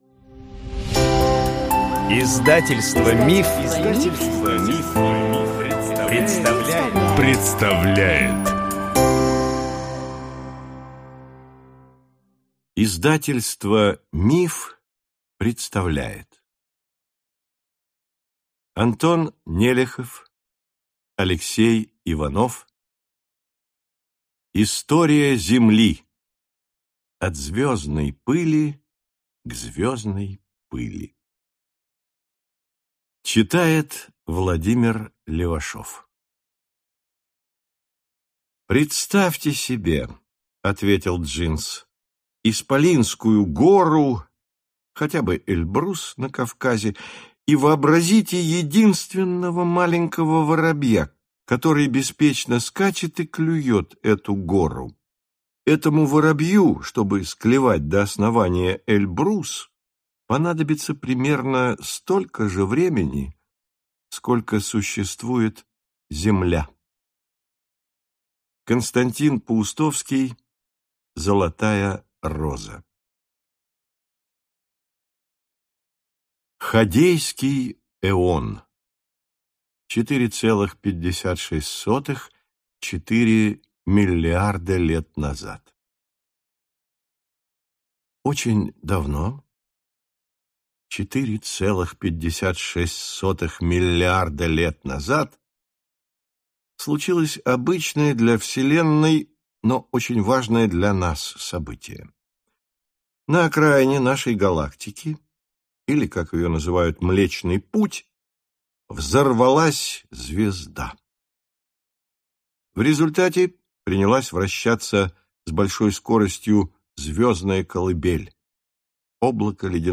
Аудиокнига История Земли. От звездной пыли к звездной пыли | Библиотека аудиокниг